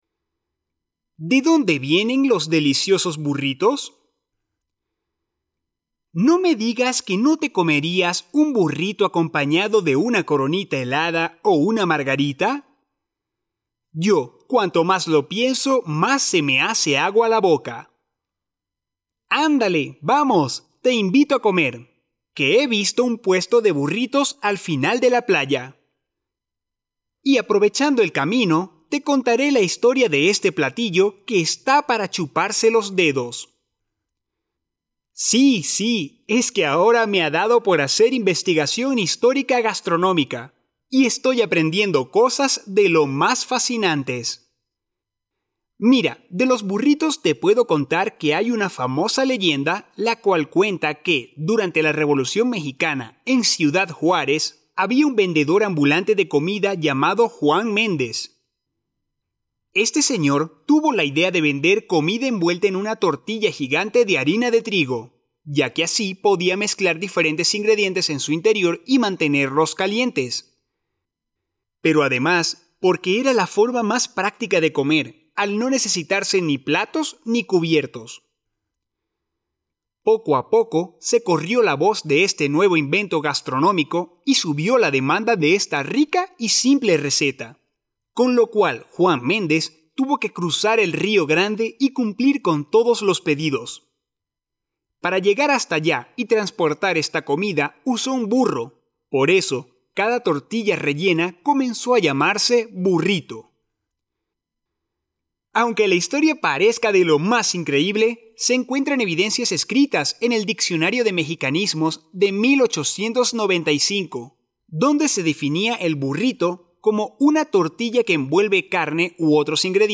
Spanish online reading and listening practice – level C1
audio by a Latin American voice professional.